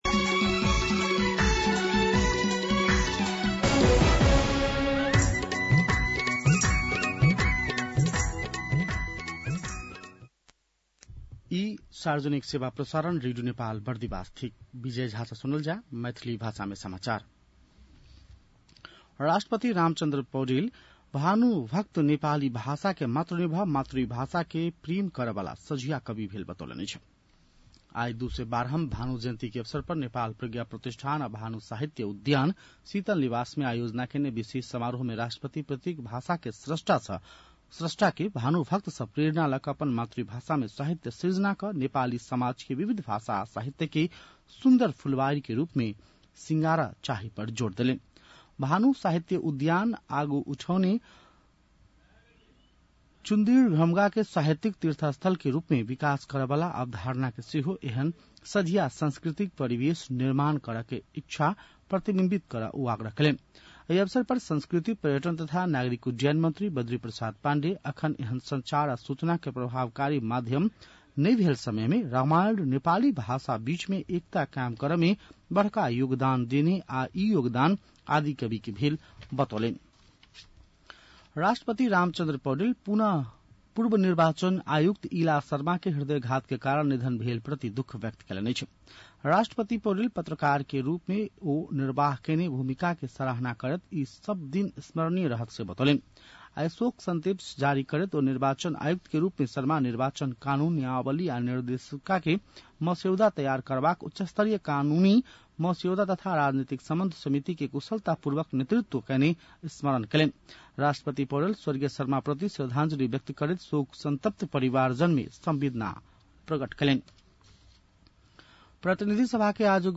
मैथिली भाषामा समाचार : २९ असार , २०८२